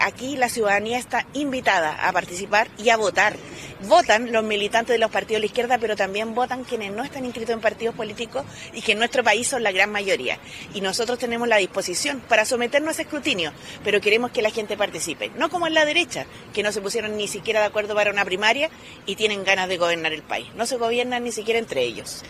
La candidata del Partido Comunista y Acción Humanista, Jeannette Jara, también comenzó la jornada con un “volanteo” en un metro en la comuna de Peñalolén.